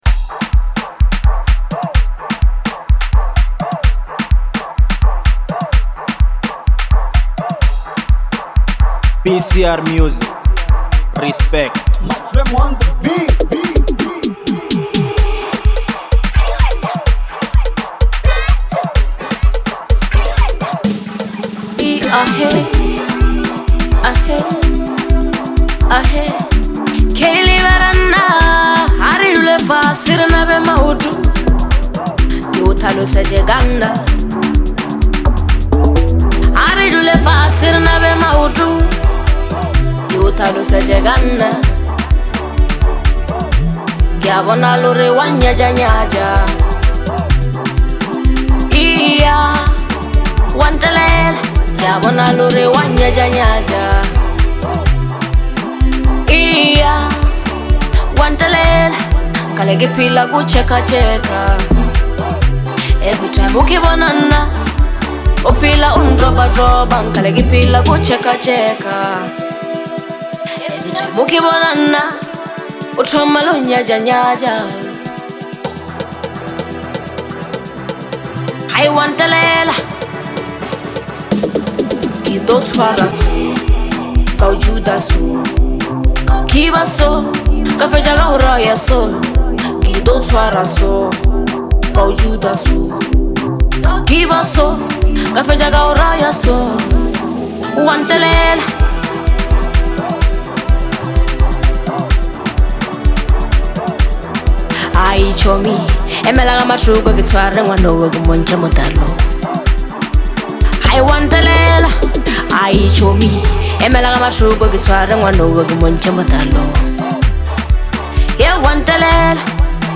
Bolo HouseBolobedu House
signature, commanding vocals
A hypnotic beat